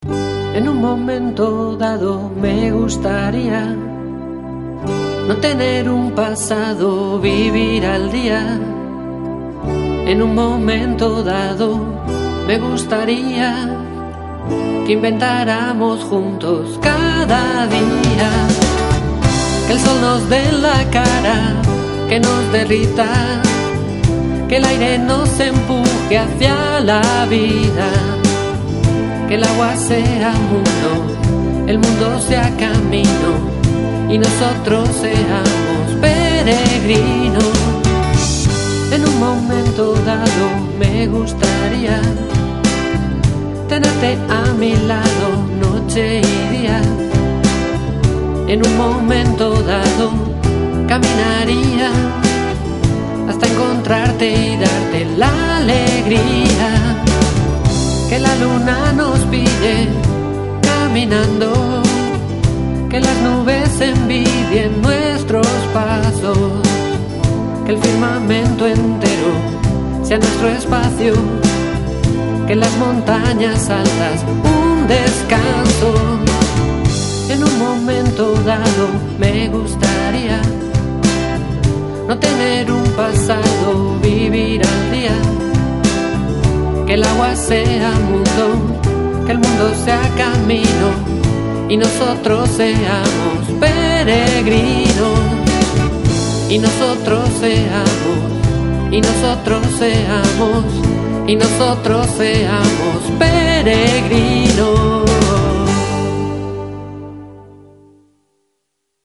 Cantautor madrileño